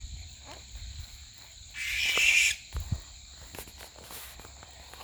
American Barn Owl (Tyto furcata)
Detailed location: Complejo Guaiquiraró
Condition: Wild
Certainty: Photographed, Recorded vocal
Lechuza-de-campanario-3_1.mp3